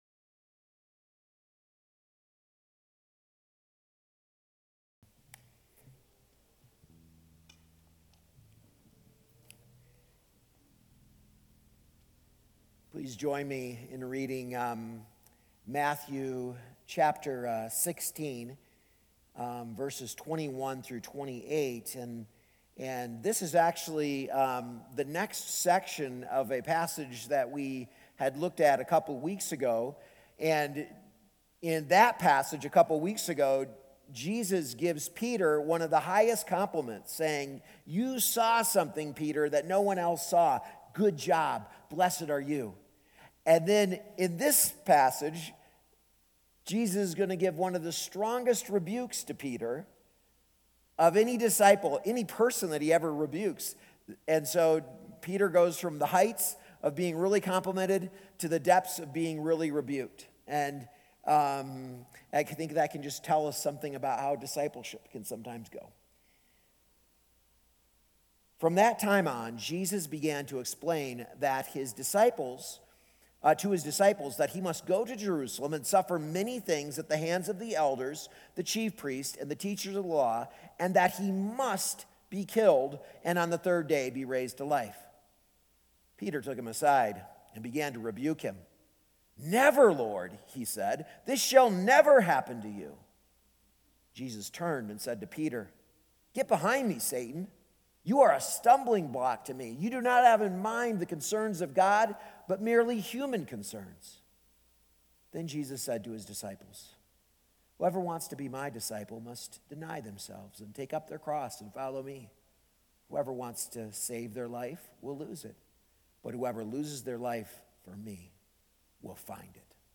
A message from the series "Encountering the Cross."